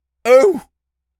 seal_walrus_hurt_03.wav